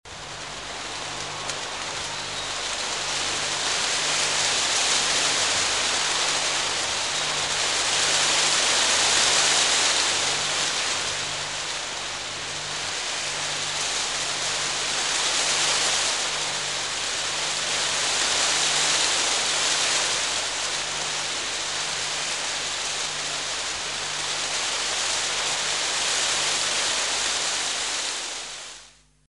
Звук ветра и дождя
И такой звук ветра есть — 29 сек